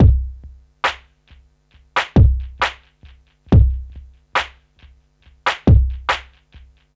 C E.BEAT 3-L.wav